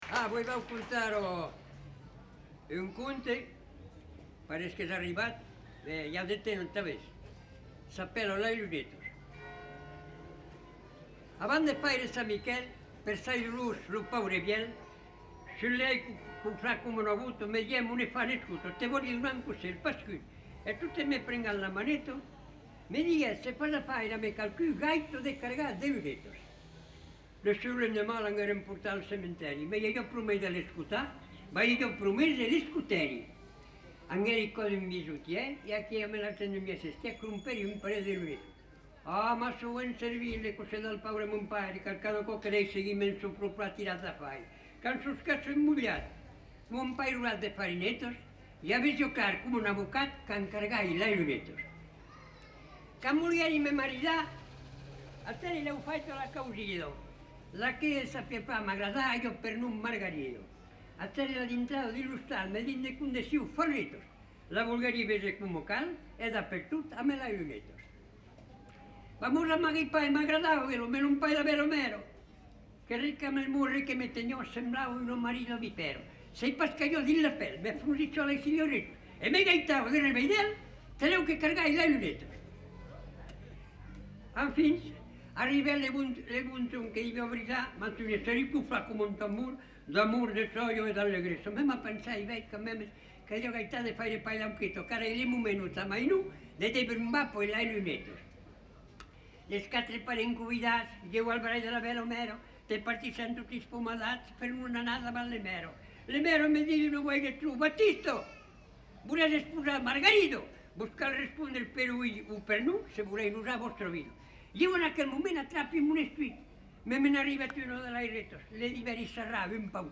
Lieu : Revel
Genre : conte-légende-récit
Type de voix : voix d'homme
Production du son : récité
Classification : monologue